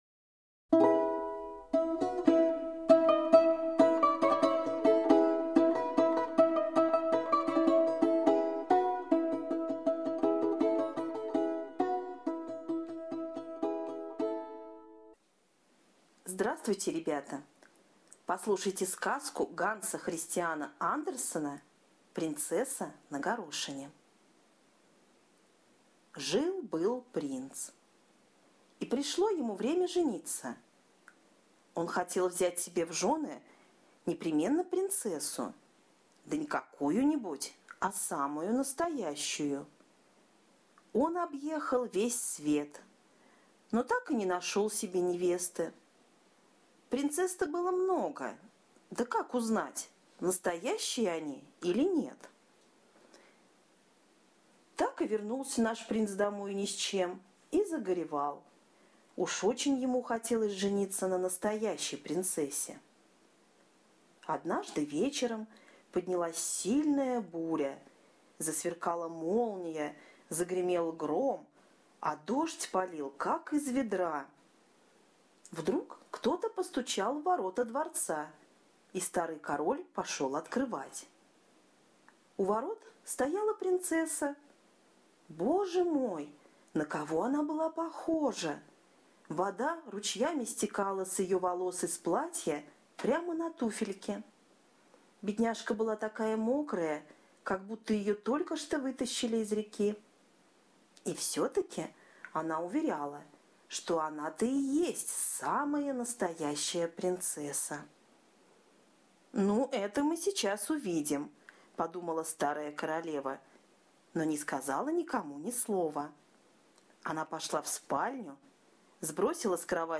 Категория: Аудиосказки